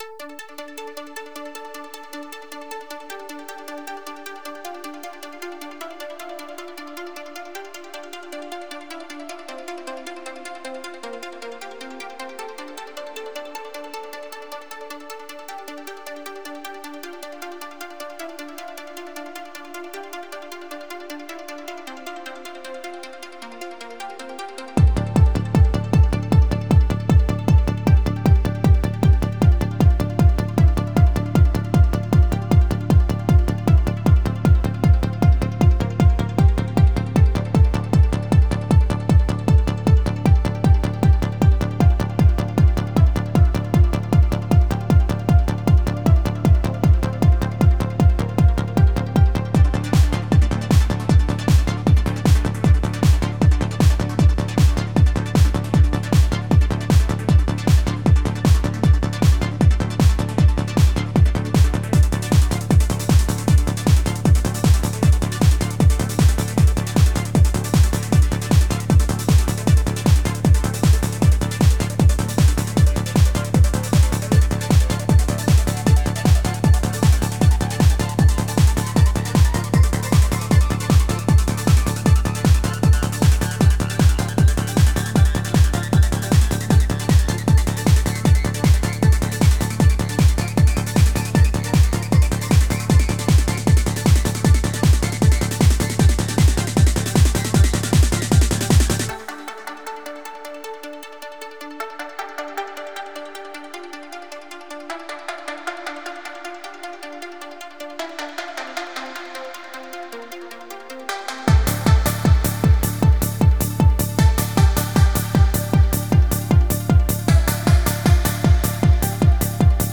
OldSchool Trance[?]
Ist noch ein ziemlicher Rohbau,was Automationen und Crashes etc. angeht, aber ich wollte mich diemal nicht mit kleingefrickel aufhalten, sonder erstmal ein halbwegs brauchbares arrangement(was ich mir echt mal dauerhaft angewöhnen muss ).